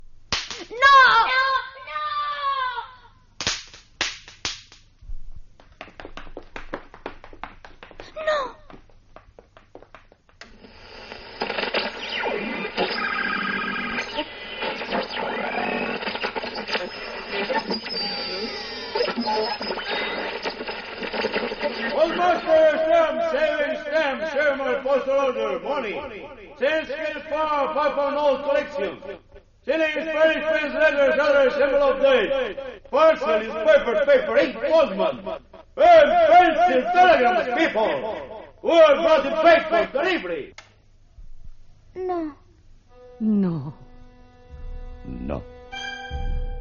Ficció
Narració construïda amb els efectes de so i la paraula "no".